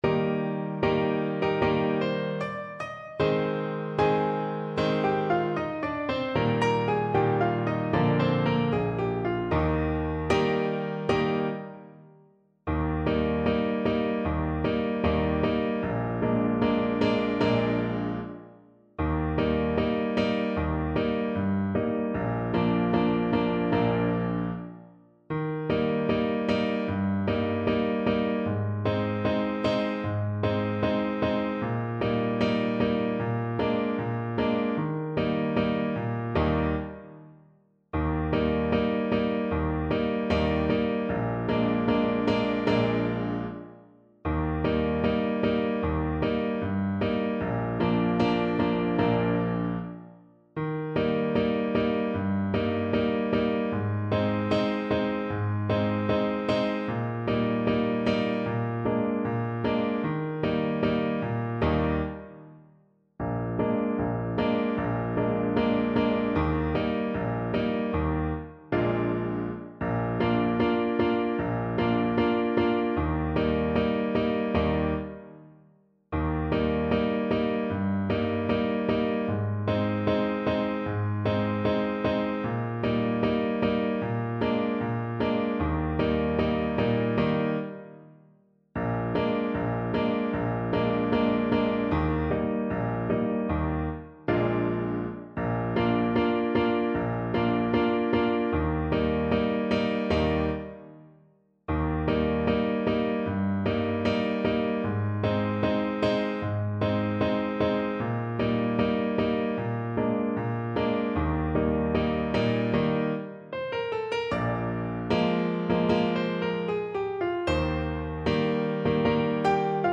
Voice 1Voice 2
Moderato = 76
4/4 (View more 4/4 Music)
Bb4-G6
World (View more World Voice Music)
Mariachi Music for Voice